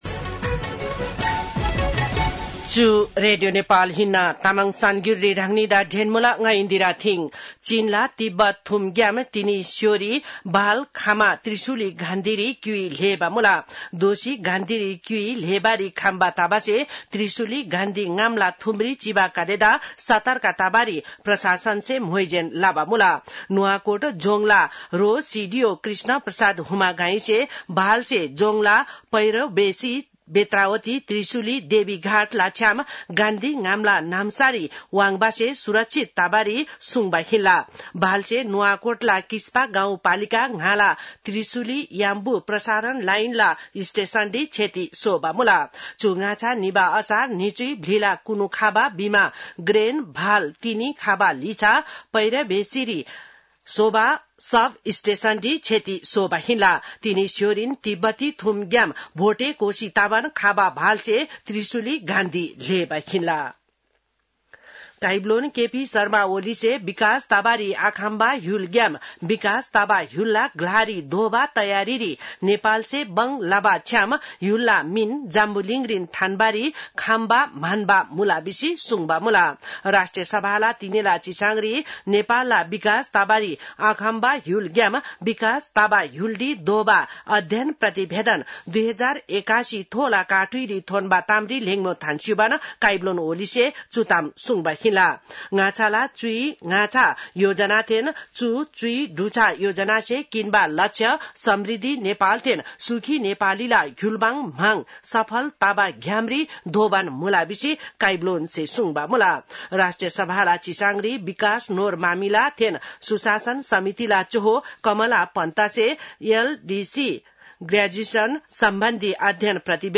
तामाङ भाषाको समाचार : १४ साउन , २०८२
Tamang-news-4-14.mp3